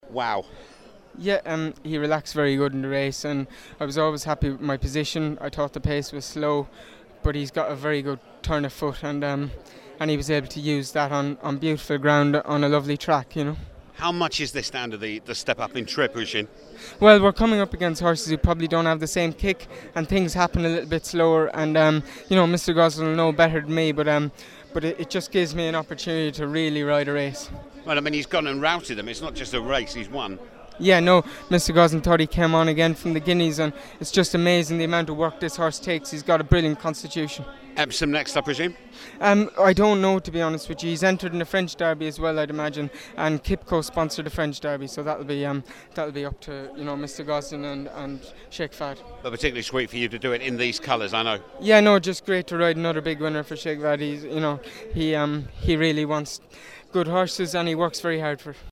Listen to the reaction of  the winning jockey Oisin Murphy